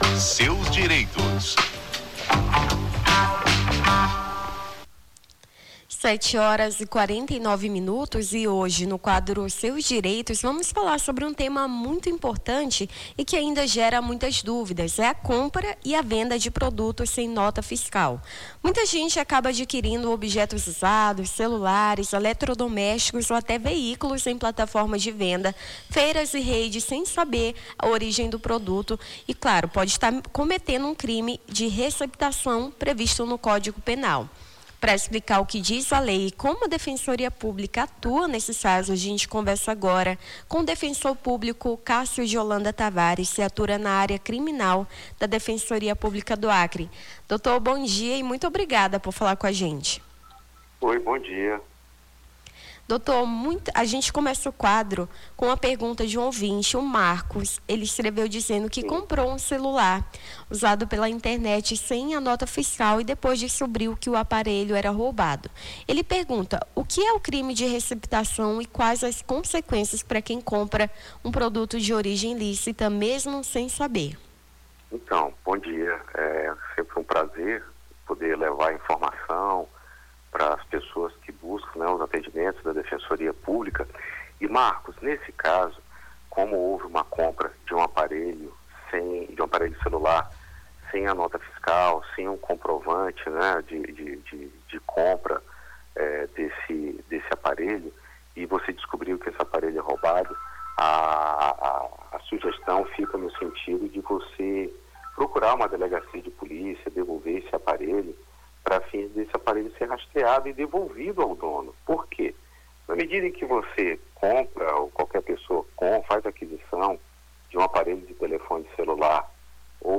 o Jornal da Manhã conversa com o defensor público